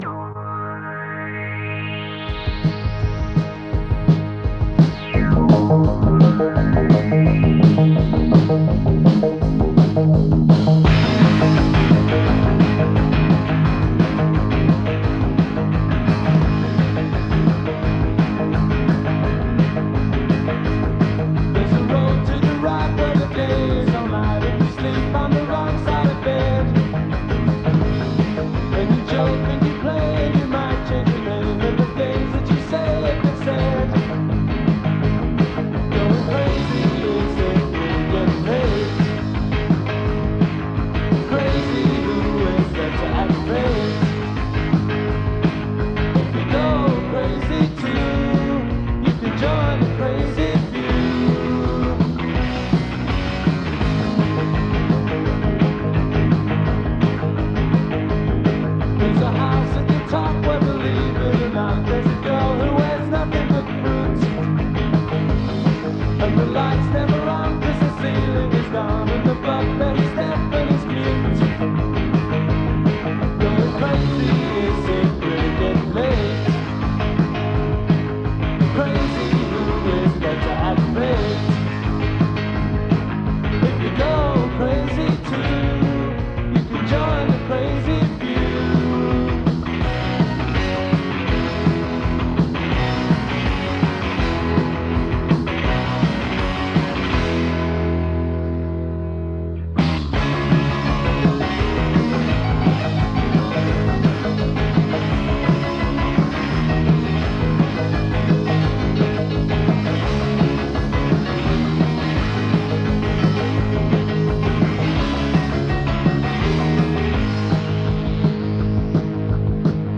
Am I the only one who recorded it off the radio?
I love the bass and the live feel to the recordings.